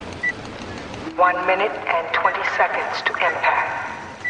Woman over PA - one minute and 20 seconds to impact.ogg
Original creative-commons licensed sounds for DJ's and music producers, recorded with high quality studio microphones.
[woman-over-pa]-one-minute-and-20-seconds-to-impact_lwz.mp3